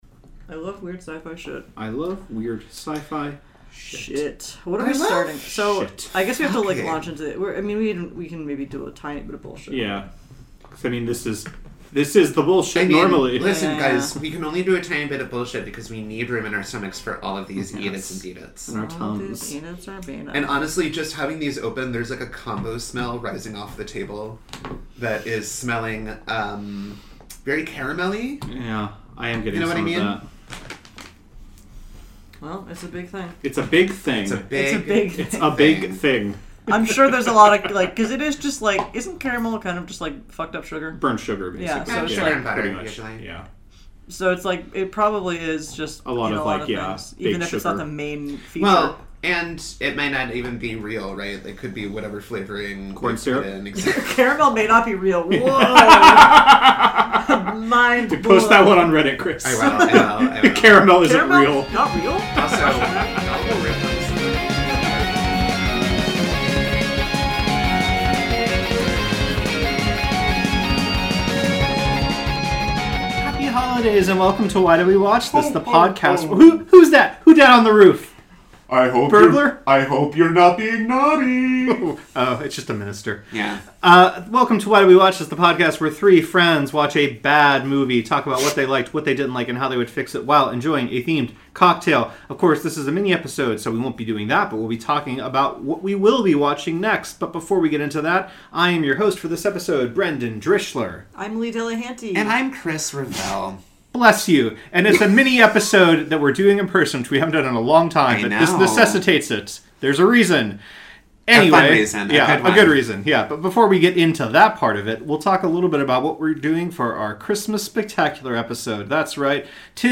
Three friends praising, destroying, and fixing the messiest, crappiest movies known to humanity. Oh, and we drink a lot, too.